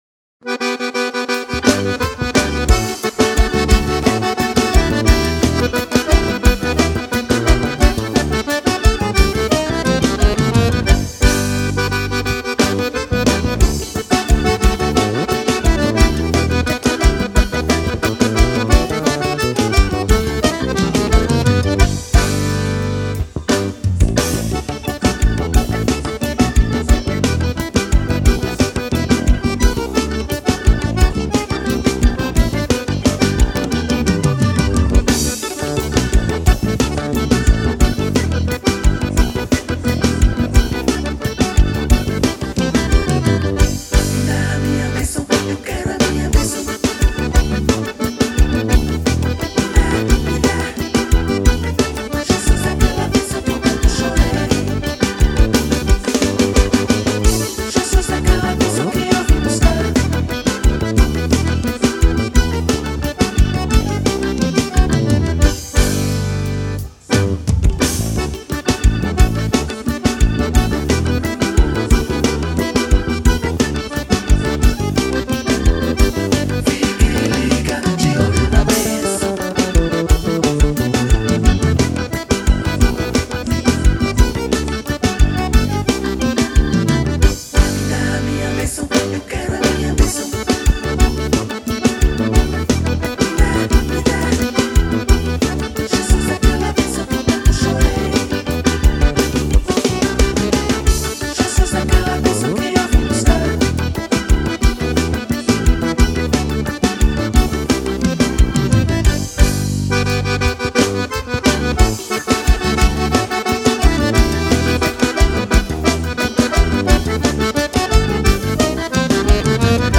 Louvor pentecostal